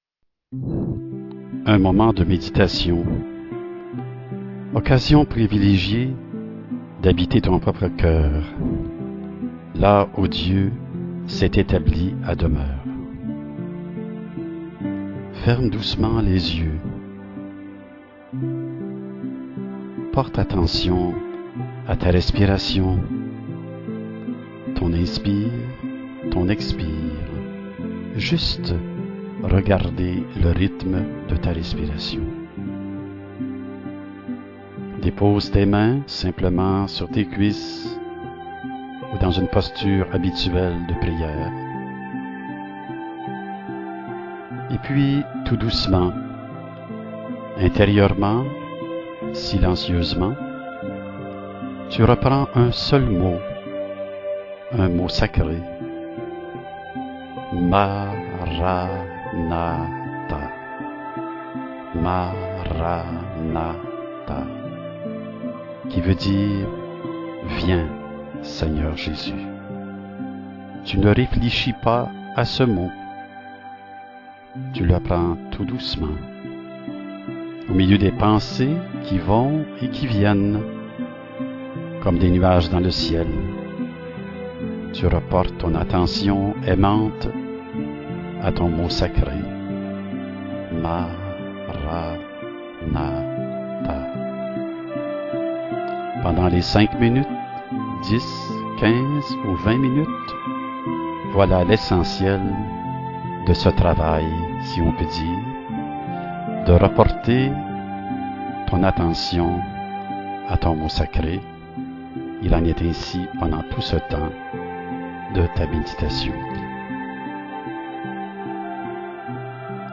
méditer en silence jusqu'au retour de la musique selon le temps choisi 5,10,15 ou 20 minutes avec le mantra "Maranatha".